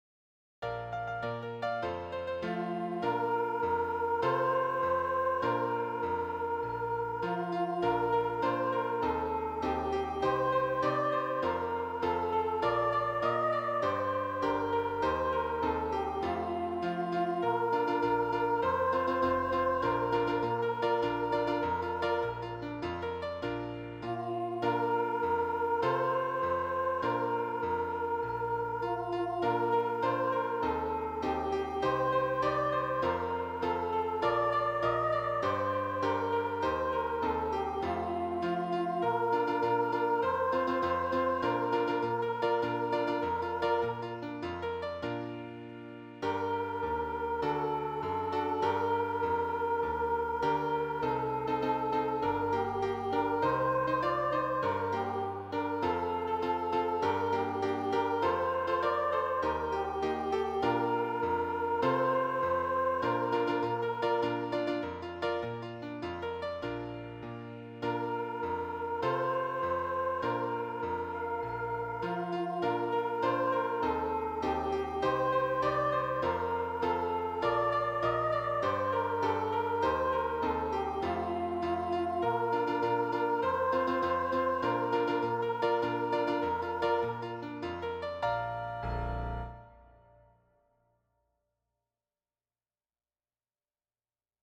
There are also demo MP3 files of the three songs.